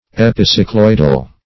Epicycloidal \Ep`i*cy*cloid"al\, a.